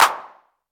normal-hitclap.ogg